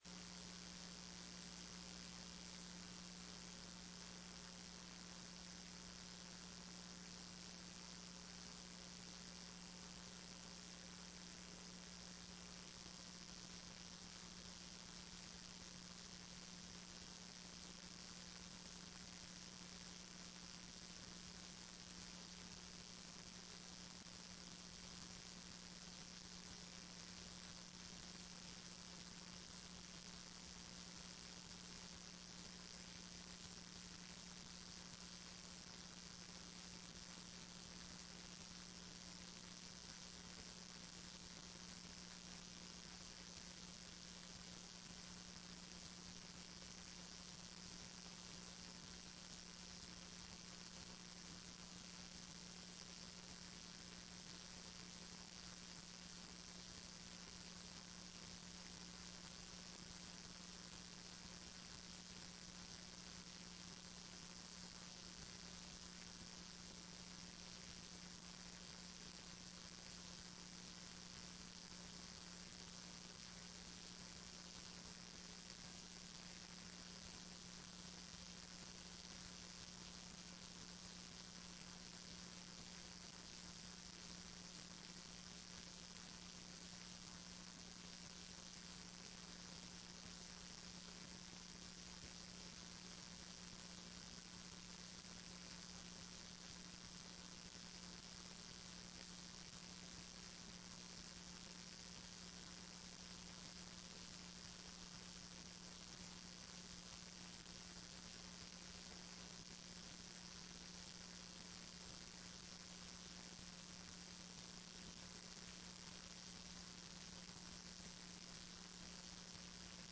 TRE-ES - Áudio da sessão 22.10.14